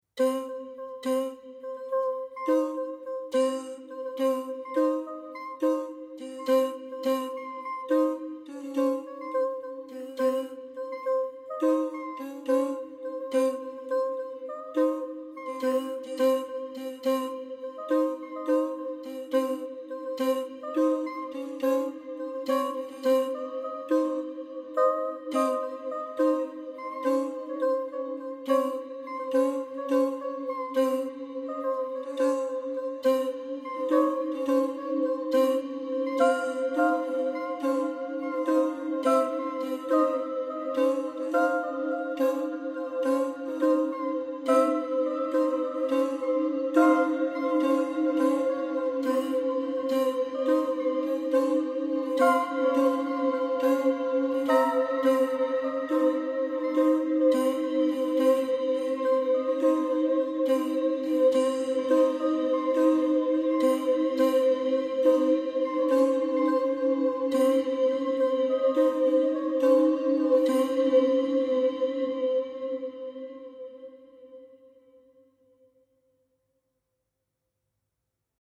only voices